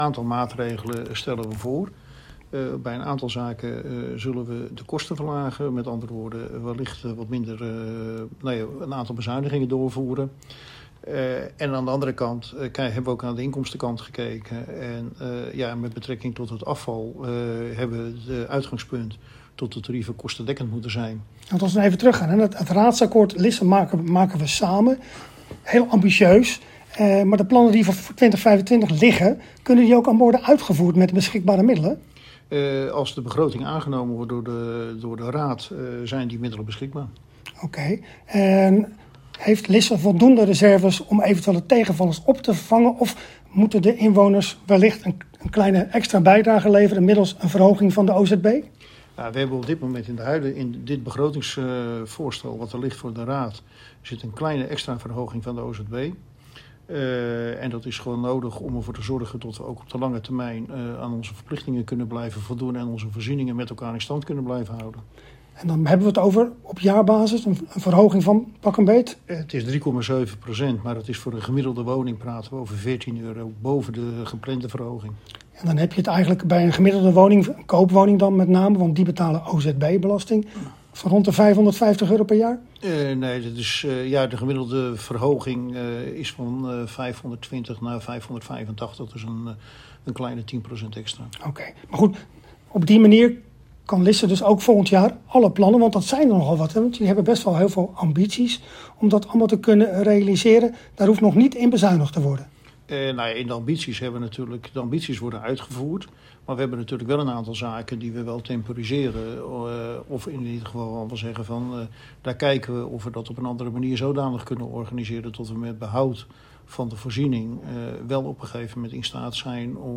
Hieronder het radio-interview met wethouder Kees van der Zwet: